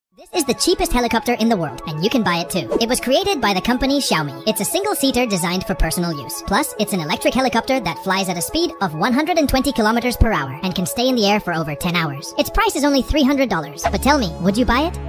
This is cheapest helicopter 🚁 sound effects free download